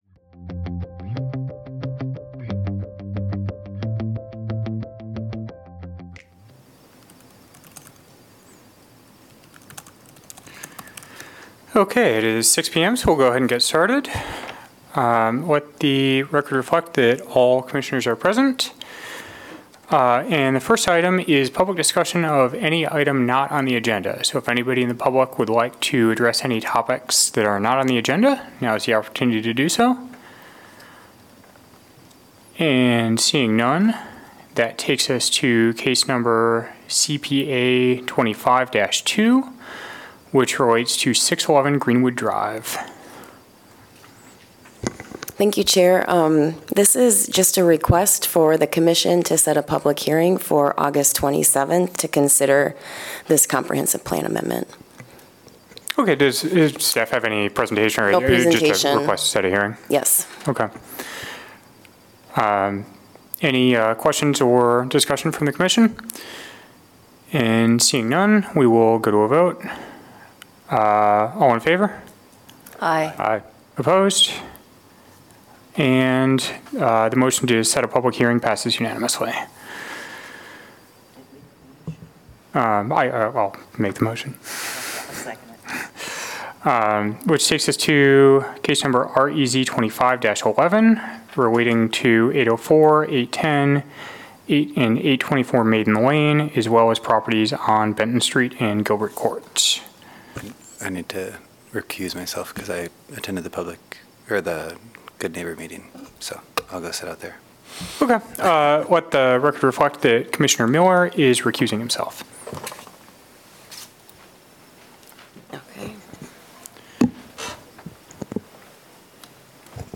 Regular semi-monthly meeting of the Planning and Zoning Commission.